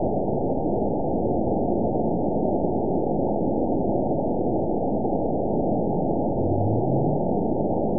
event 919729 date 01/19/24 time 21:23:19 GMT (1 year, 4 months ago) score 9.45 location TSS-AB02 detected by nrw target species NRW annotations +NRW Spectrogram: Frequency (kHz) vs. Time (s) audio not available .wav